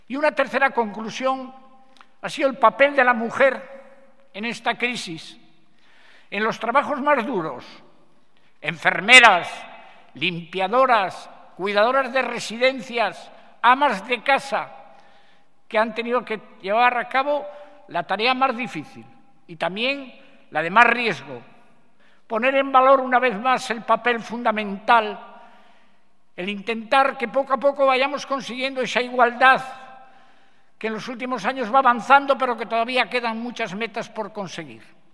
Audio del presidente de Cantabria, Miguel Ángel Revilla
El presidente de Cantabria, Miguel Ángel Revilla, y el vicepresidente, Pablo Zuloaga, han tomado la palabra en el acto para reivindicar, entre otras cosas, el papel esencial de las mujeres durante la pandemia en diferentes ámbitos como los hospitales, los centros de salud, las residencias de mayores o los hogares.